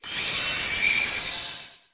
P3D-Legacy / P3D / Content / Sounds / Cries / 873.wav